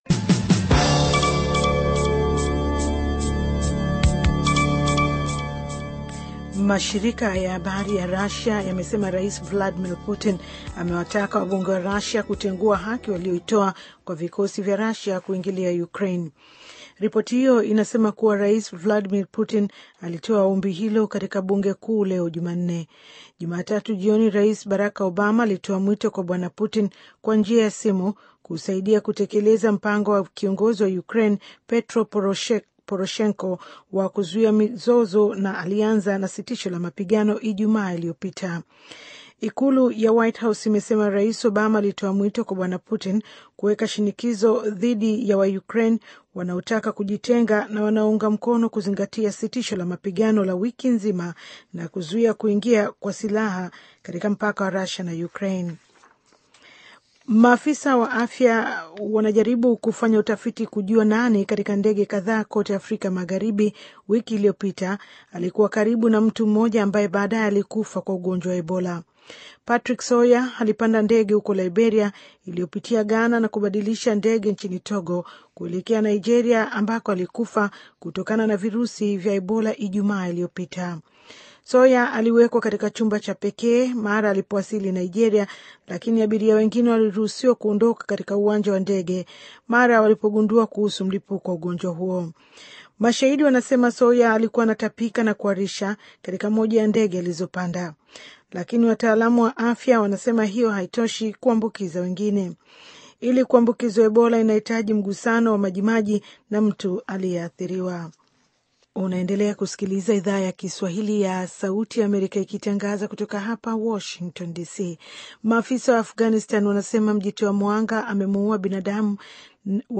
Taarifa ya Habari VOA Swahili - 5:56